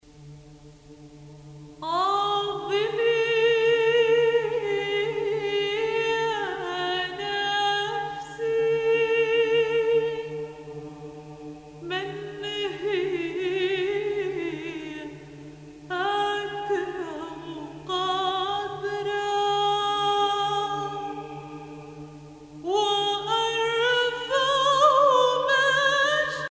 circonstance : Noël, Nativité
Pièce musicale éditée